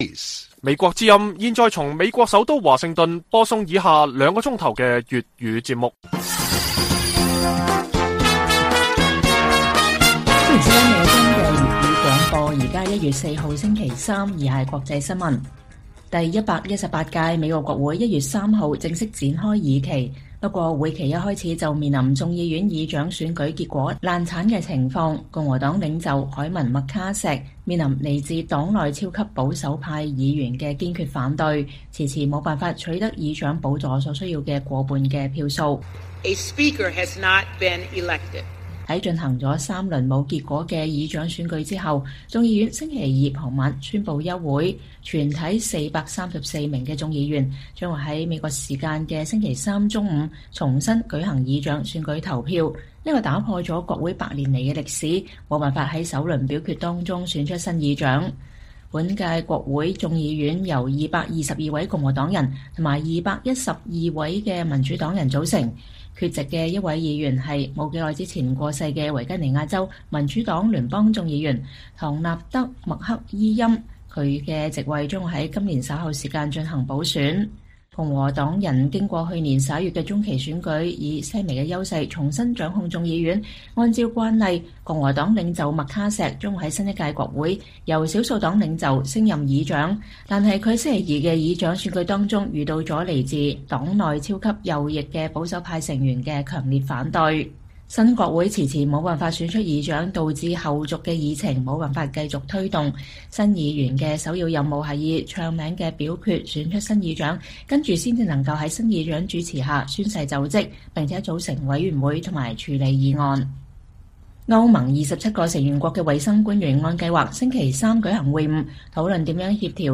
粵語新聞 晚上9-10點: 118屆國會正式開議，共和黨議員麥卡錫的議長選舉受到黨內重挫